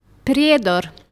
Prijedor (Serbian Cyrillic: Приједор, pronounced [prijěːdor]
Bs-Prijedor.ogg.mp3